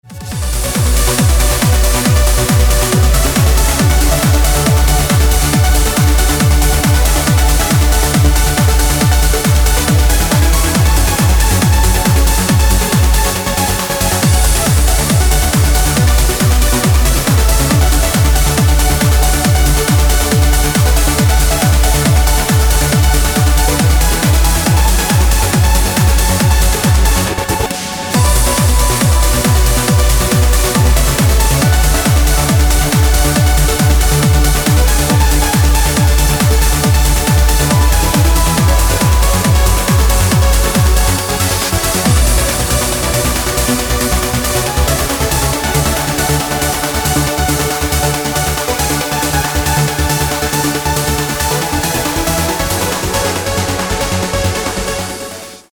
• Качество: 256, Stereo
громкие
dance
Electronic
электронная музыка
без слов
Trance